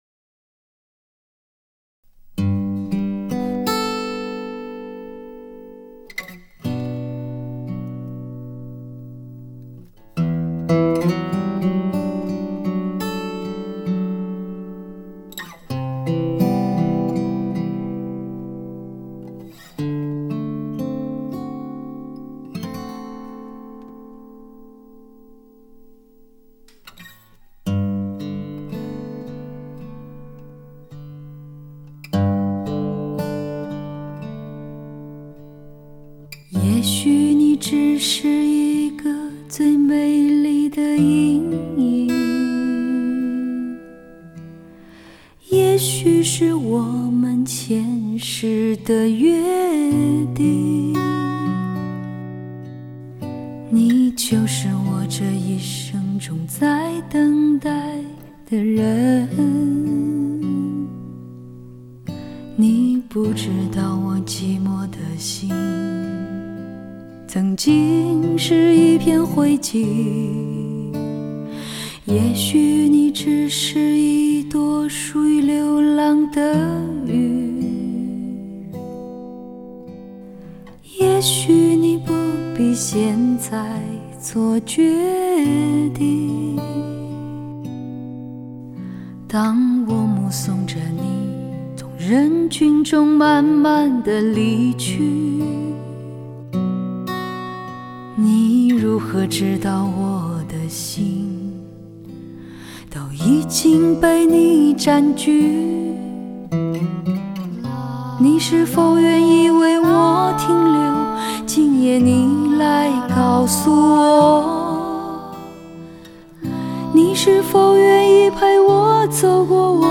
深厚的演唱功底 充满情感的声音媚力 极度磁性的嗓音特质